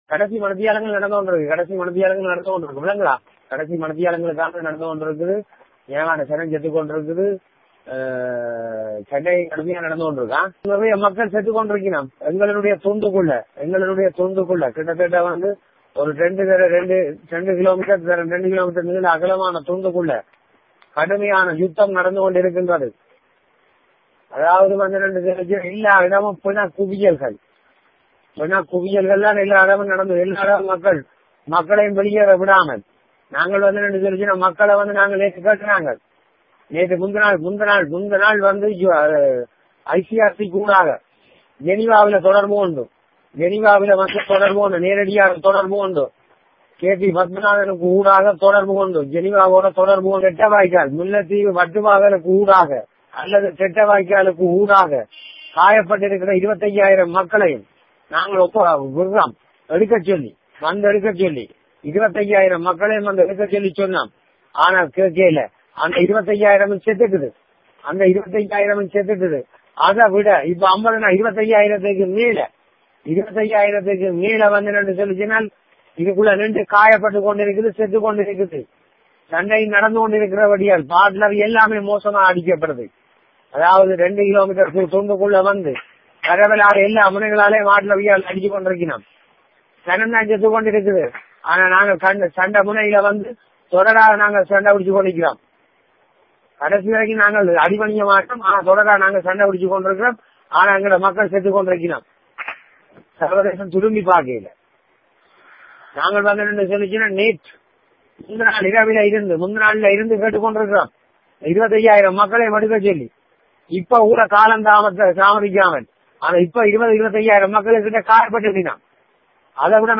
Voice: Soosai's message to media from Vanni Sunday noon